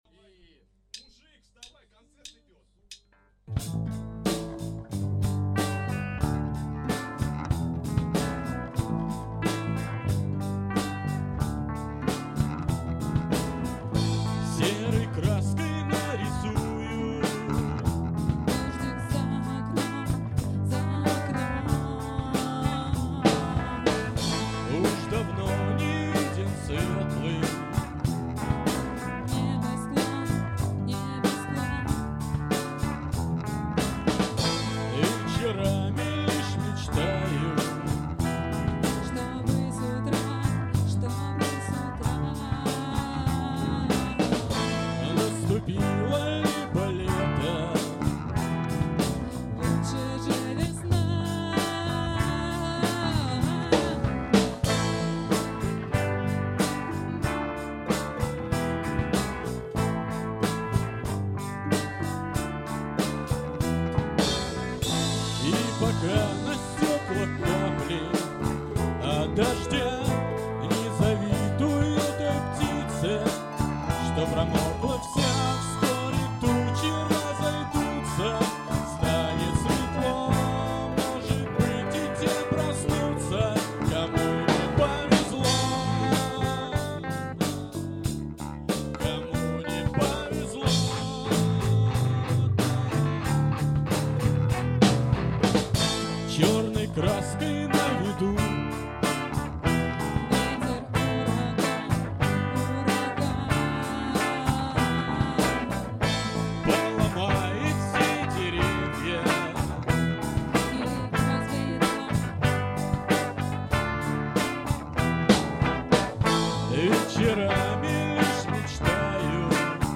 С реп.базы запись